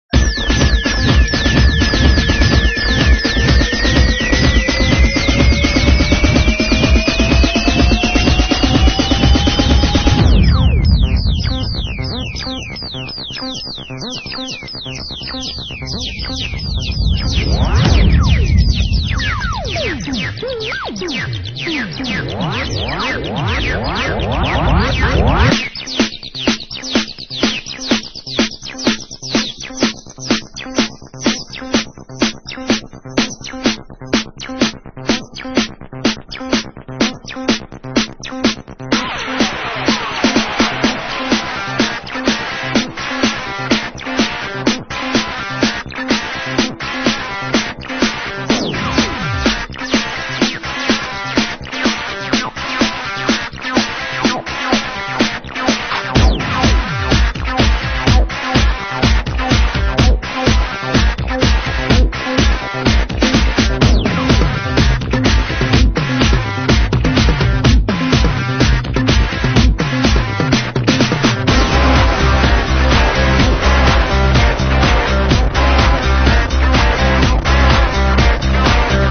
アシッド・トラックでこれでもかと引っ張り続け、後半になって狂ったようにワイルドなギターが大暴走するというフロア・ボム！！